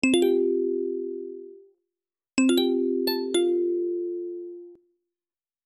Download Message sound effect for free.
Message